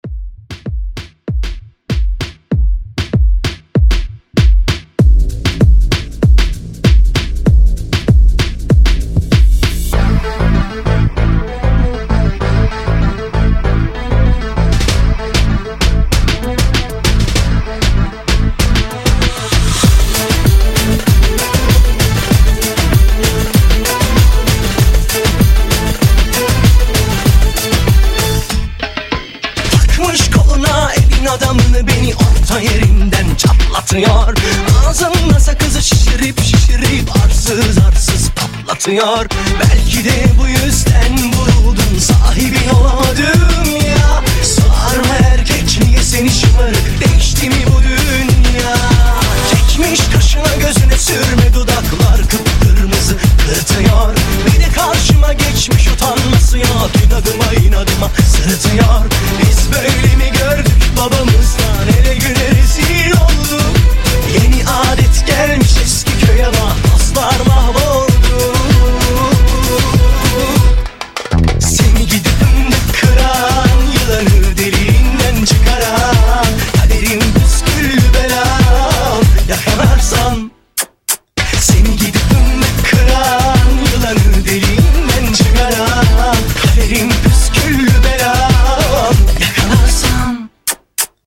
Genre: GERMAN MUSIC
Clean BPM: 85 Time